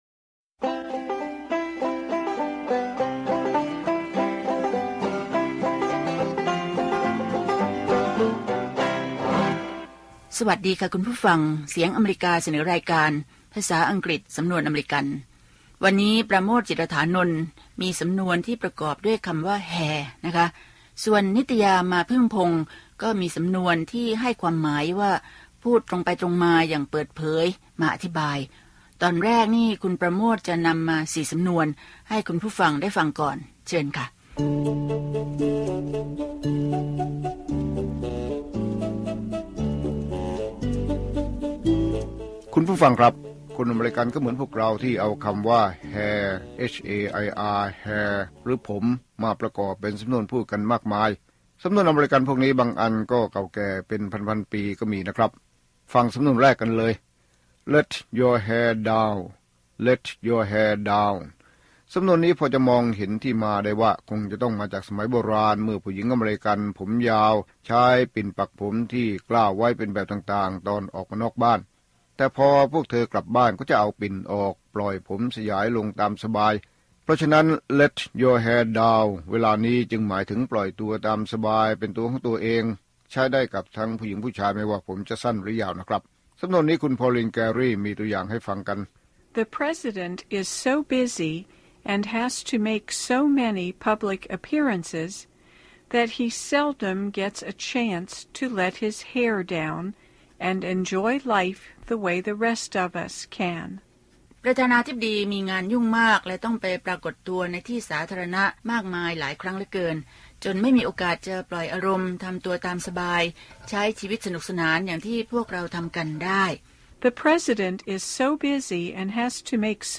ภาษาอังกฤษสำนวนอเมริกัน สอนภาษาอังกฤษด้วยสำนวนที่คนอเมริกันใช้ มีตัวอย่างการใช้ และการออกเสียงจากผู้ใช้ภาษาโดยตรง